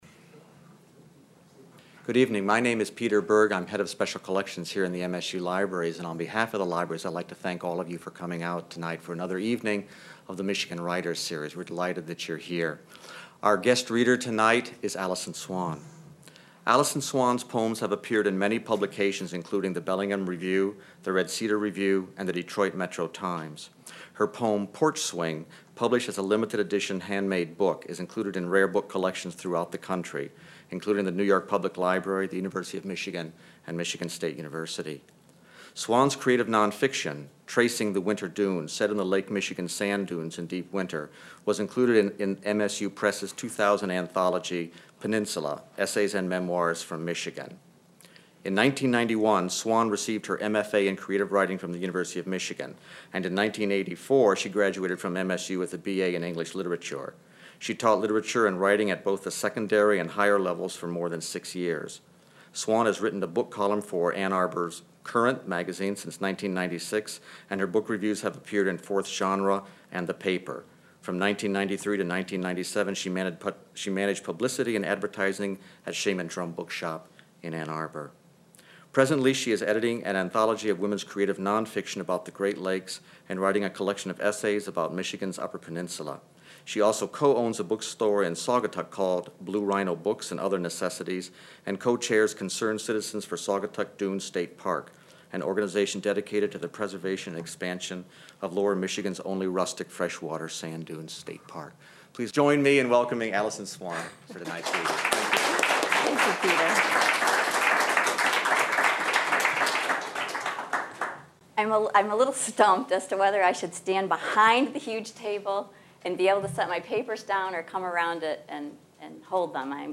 She answers questions from the audience.
Recorded at the Michigan State University Libraries by the Vincent Voice Library on Sept. 27, 2002.